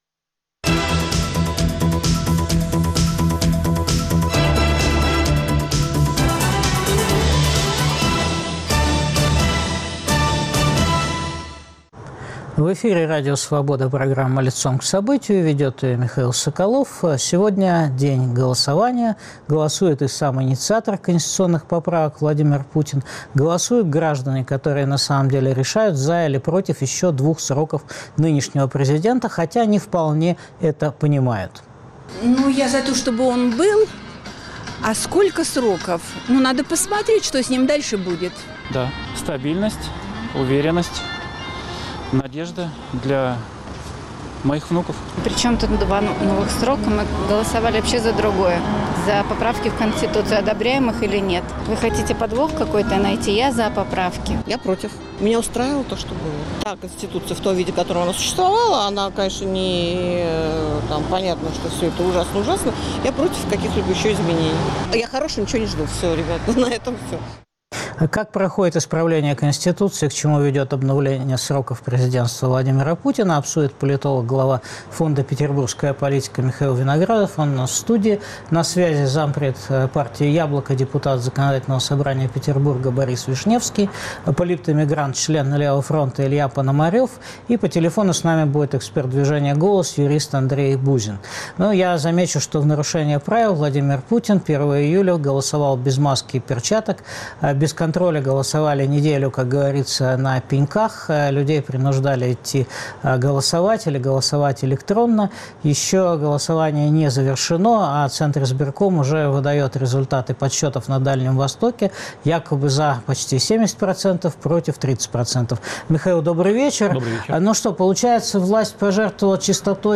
Какой конституционный режим создается в России? Что ждать от режима в России после обнуления? Обсуждают политолог